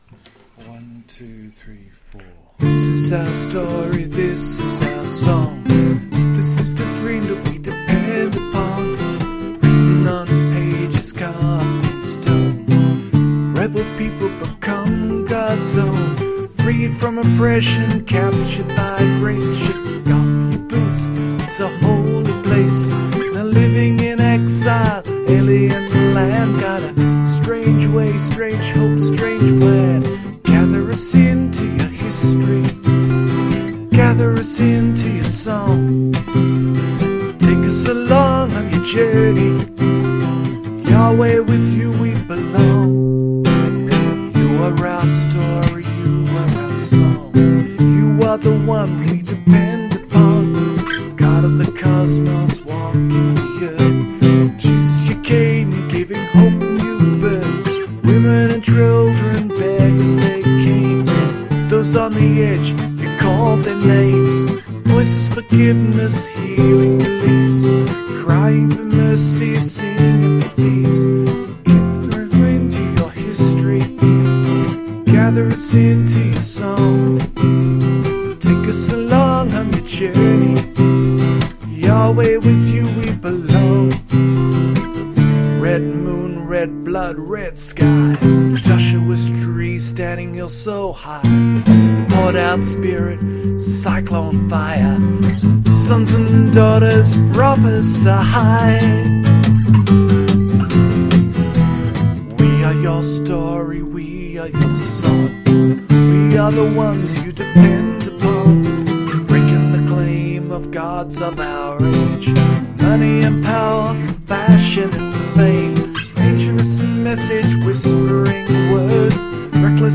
rough audio recording